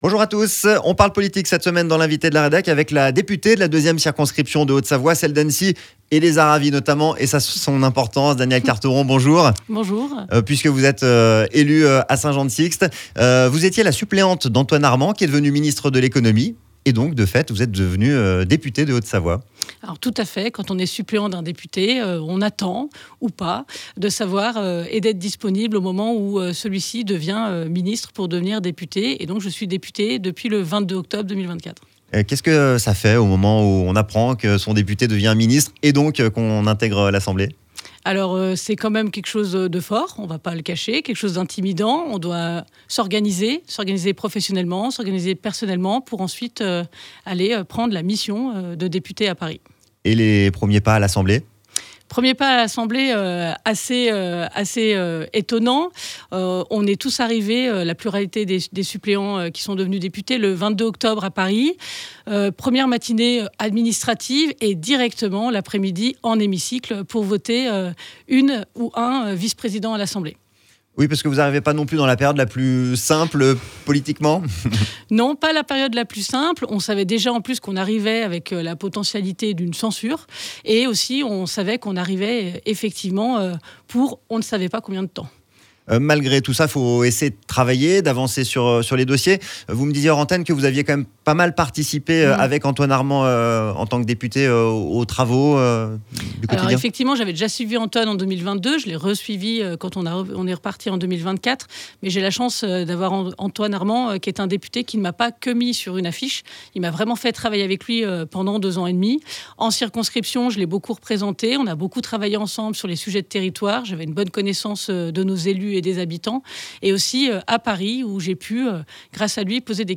Interview de Danièle Carteron, devenue Députée de Haute-Savoie.
Danièle Carteron était l’Invitée de la Rédac sur ODS Radio :